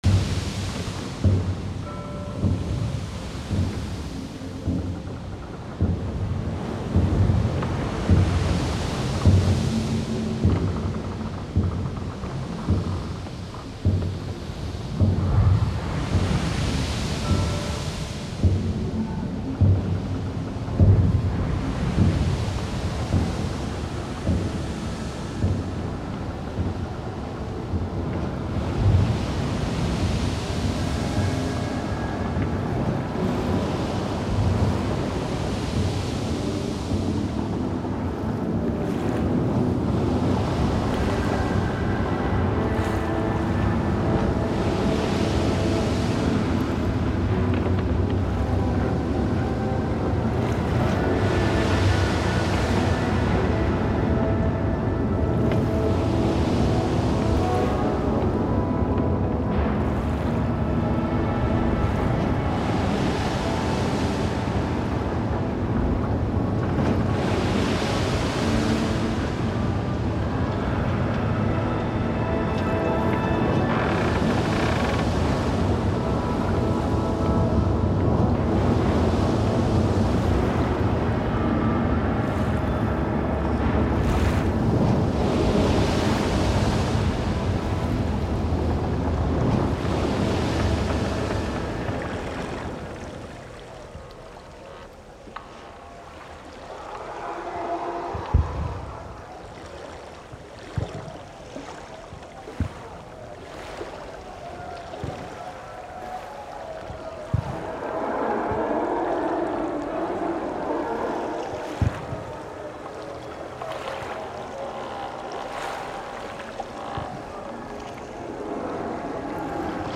sound design / storytelling